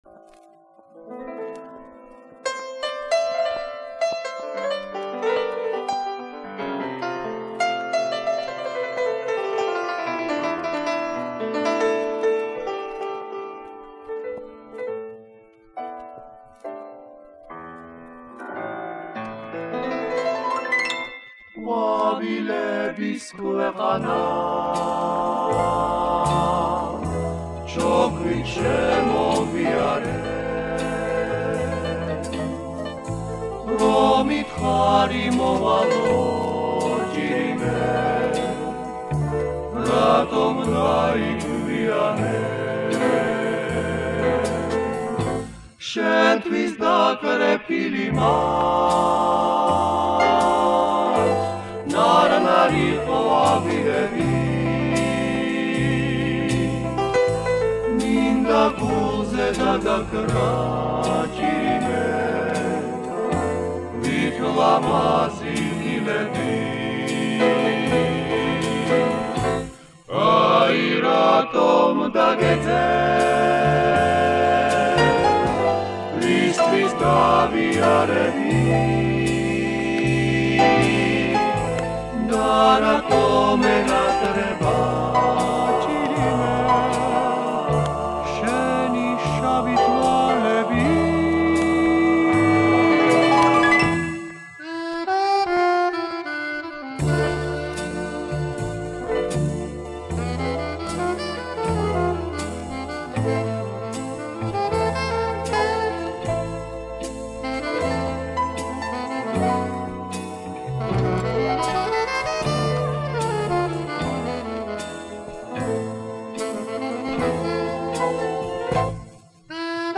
парой наших грузинских песен